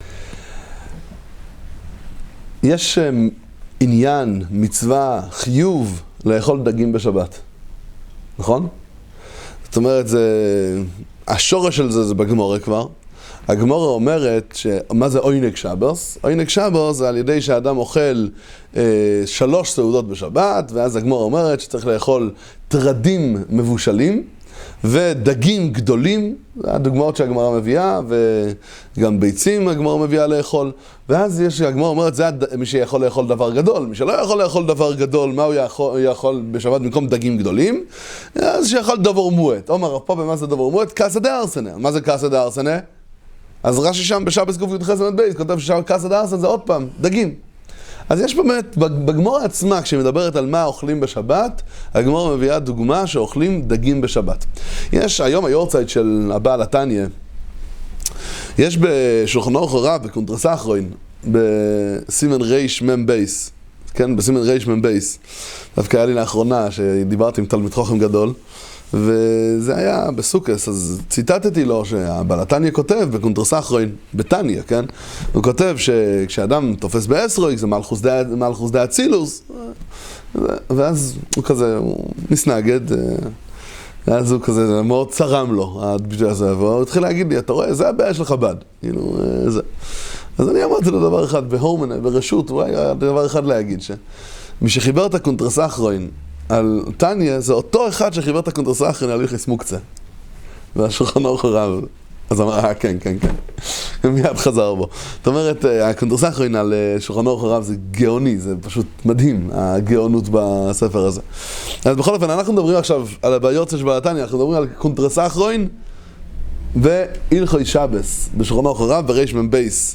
שיעורי תורה